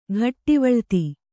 ♪ ghattivaḷti